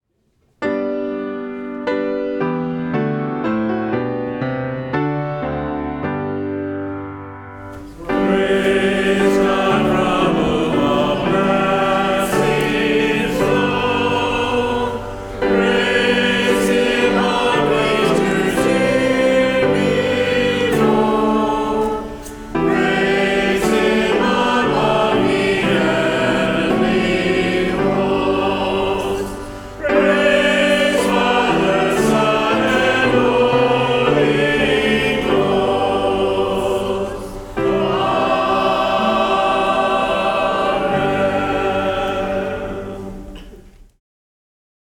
Service of Worship
Doxology